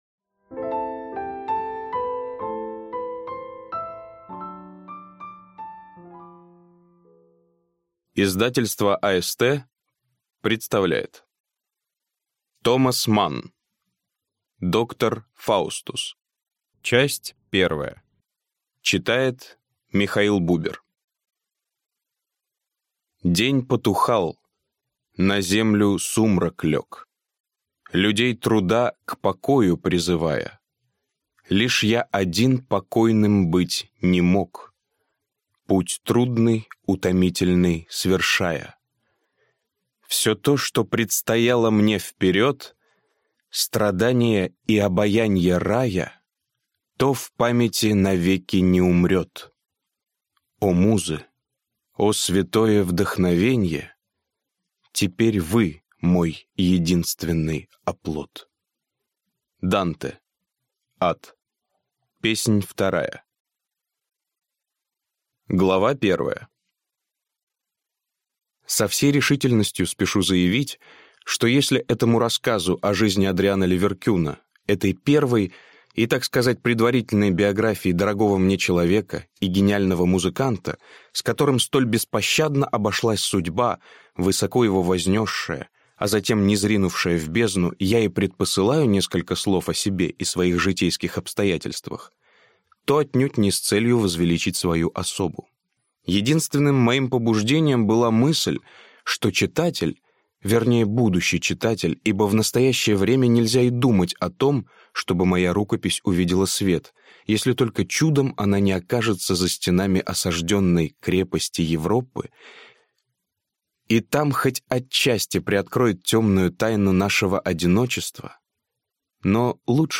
Аудиокнига Доктор Фаустус. Часть 1 | Библиотека аудиокниг